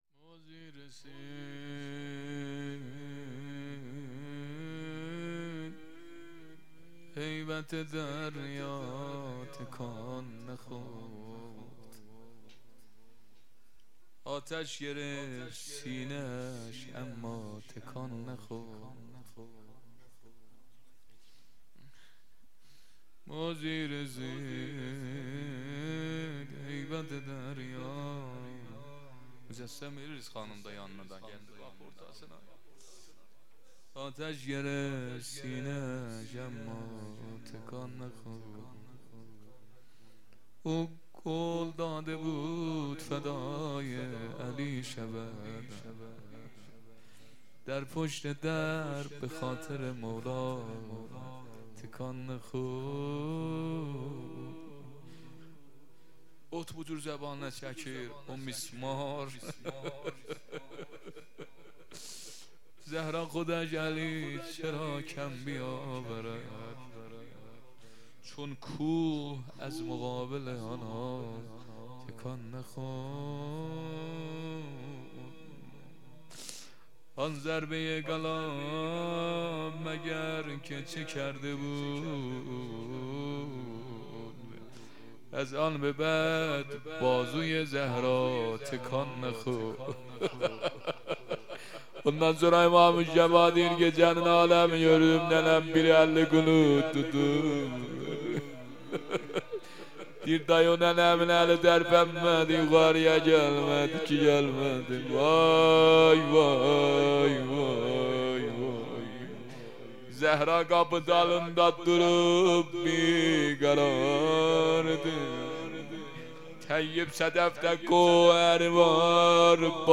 مراسم هفتگی | 15 آذر ماه 1400
روضه ترکی